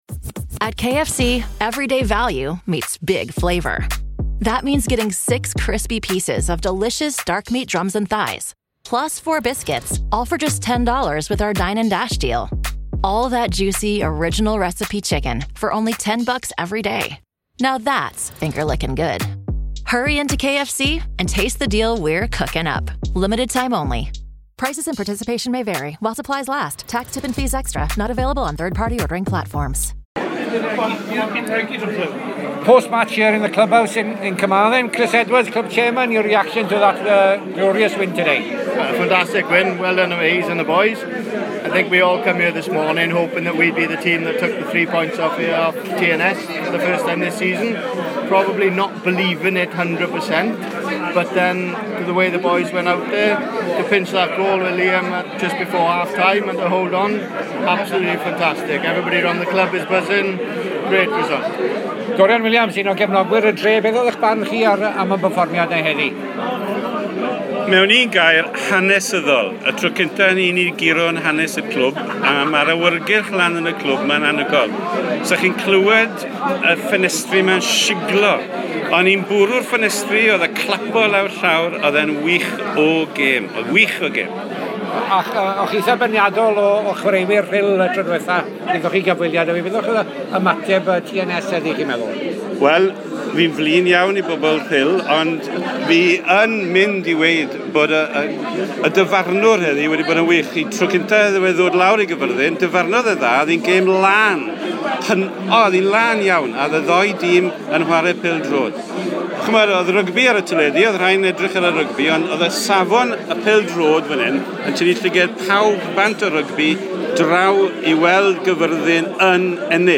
cefnogwyr ar ol gem y seintiau